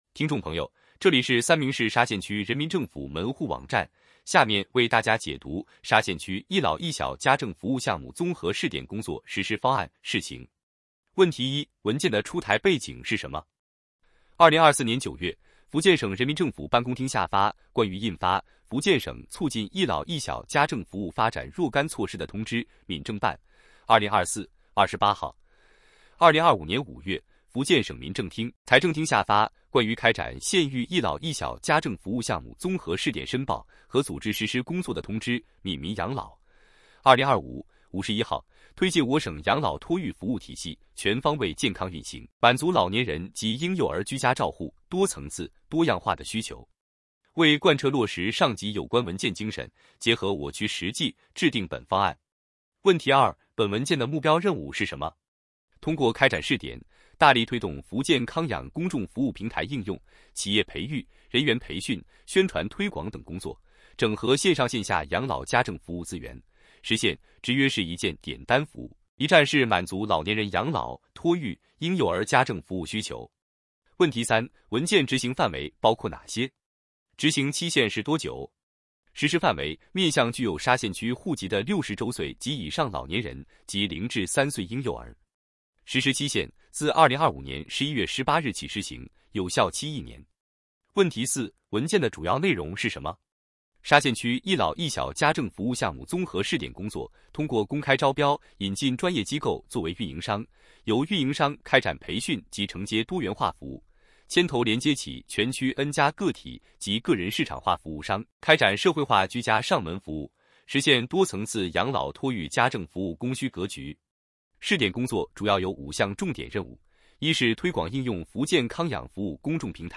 音频解读：沙县区“一老一小”家政服务项目综合试点工作实施方案（试行）